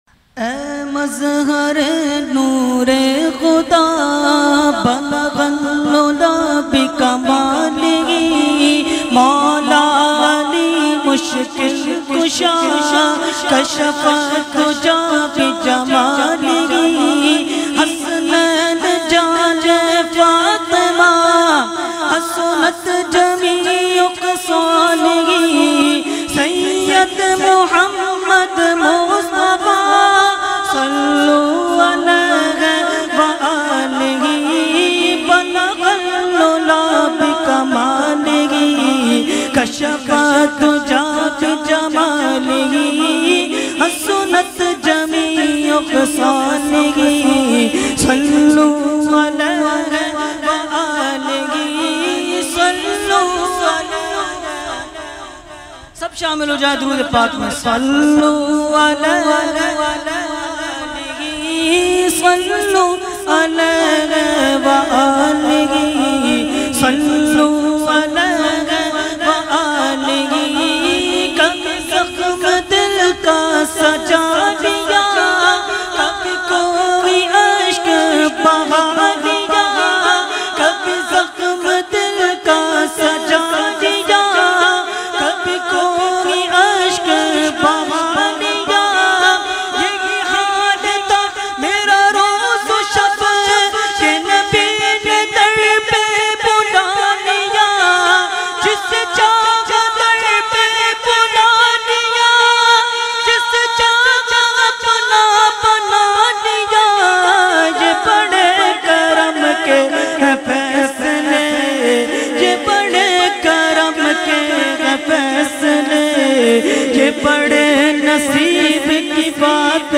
Category : Naat | Language : UrduEvent : Muharram 2020